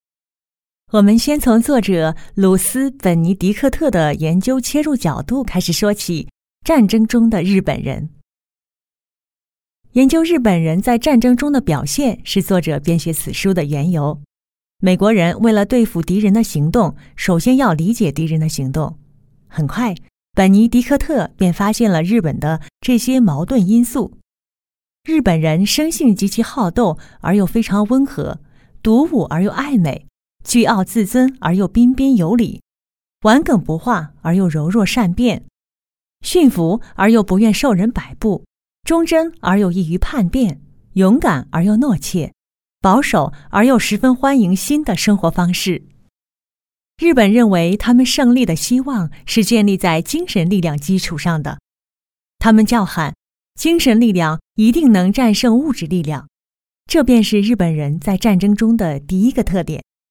女225-有声读物【故事讲解感】
女225-知性 温婉 舒缓抒情
女225-有声读物【故事讲解感】.mp3